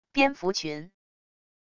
蝙蝠群wav音频